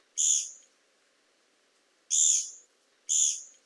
ヘルプ 詳細情報 コゲラ 大きさ スズメくらい 季節 春夏秋冬 色 黒・白 特徴 （名前は聞きなれなくても、キツツキあるいはウッドペッカーなら納得がいくのでは？）大きさはスズメほどで、日本でいちばん小さいキツツキです。「ギィー、ギィー」と鳴く声は、戸がきしむ音のようにも聞こえます。